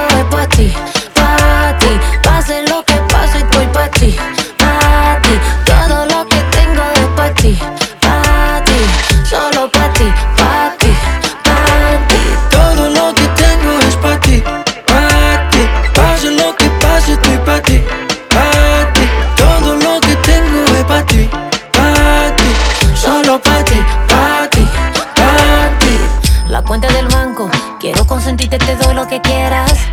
• Pop Latino